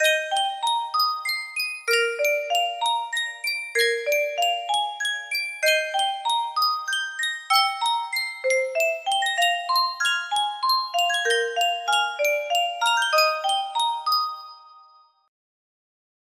Sankyo Music Box - Johann Strauss Kiss Waltz HY
Full range 60